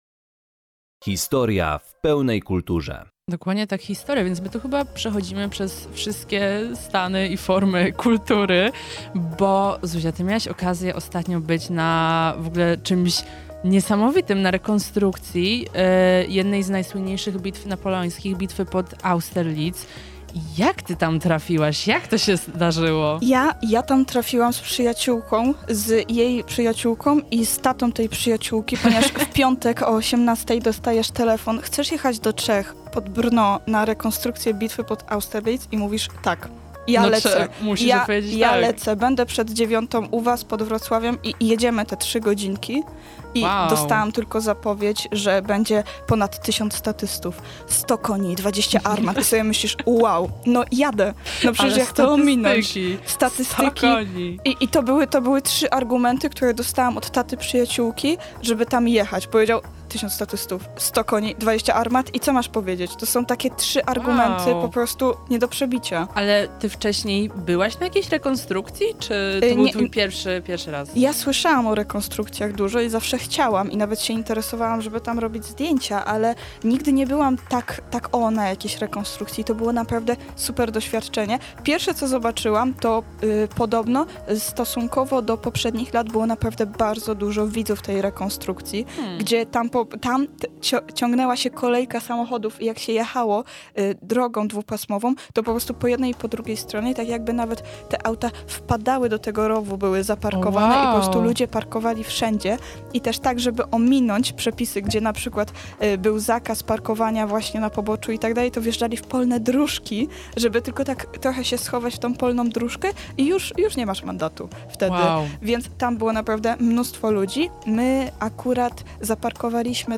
napoleon-rekonstrukcja.mp3